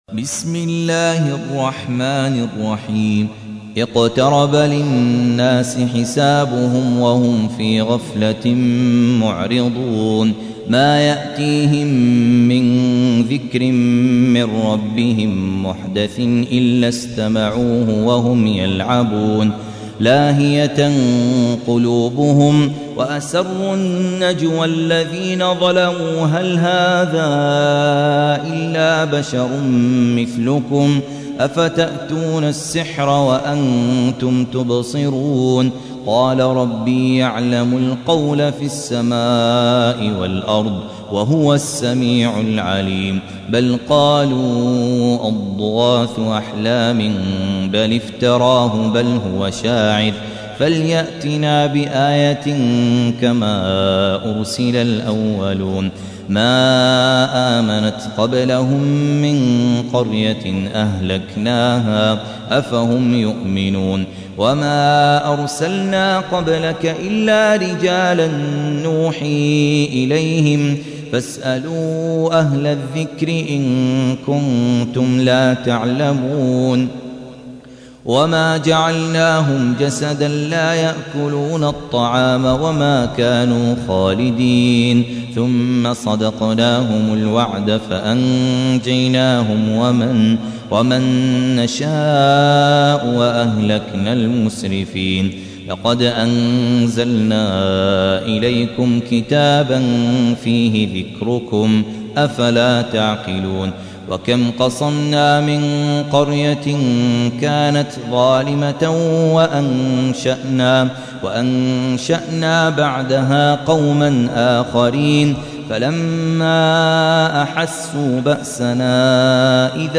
تحميل : 21. سورة الأنبياء / القارئ خالد عبد الكافي / القرآن الكريم / موقع يا حسين